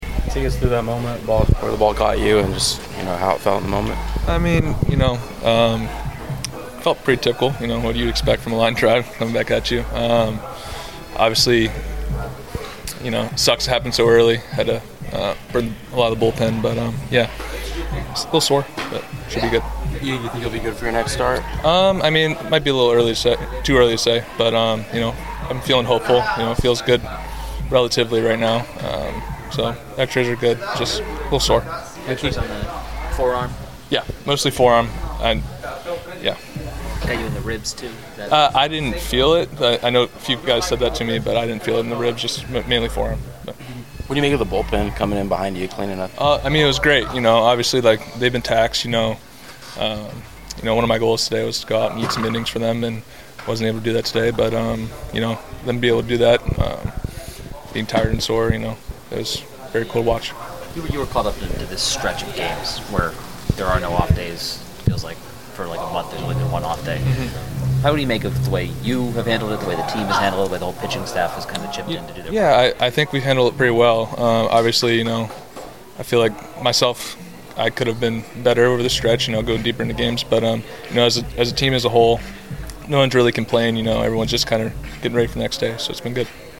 6.24.25 Ryan Bergert postgame comments